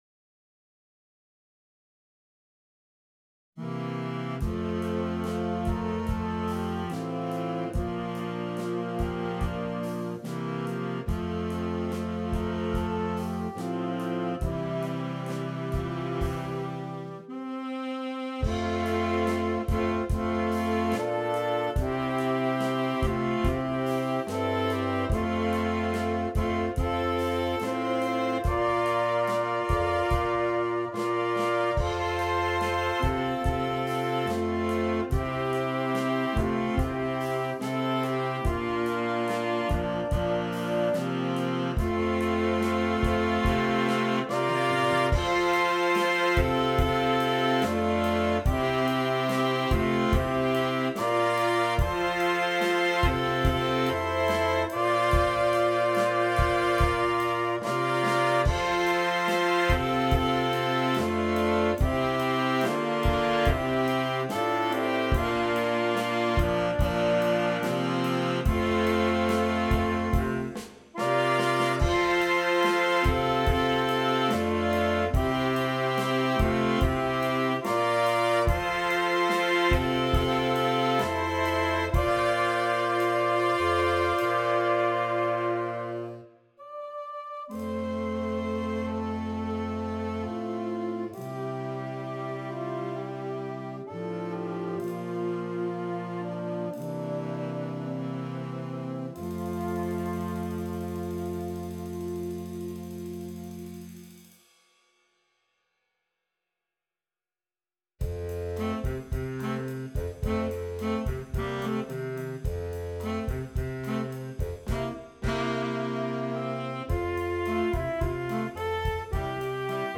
Optional Drum Set part is included.